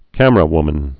(kămər-ə-wmən, kămrə-)